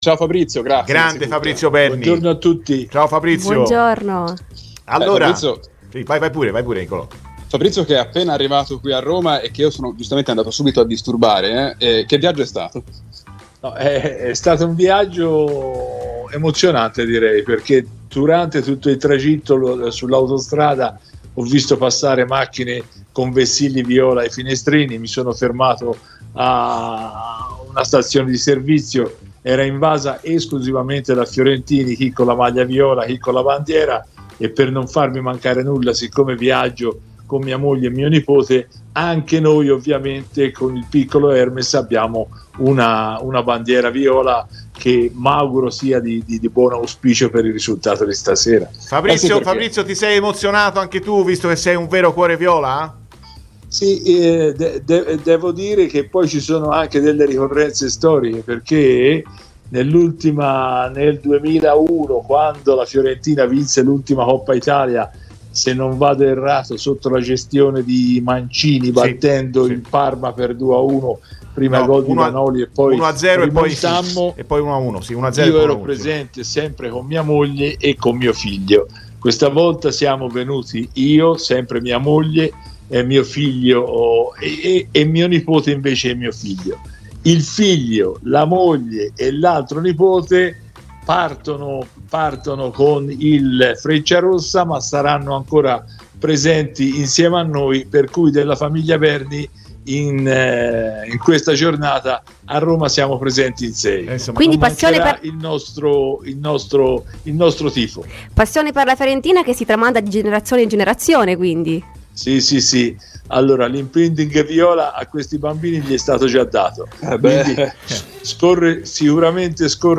Ecco le sue parole:"Sono molto emozionato per la partita di stasera.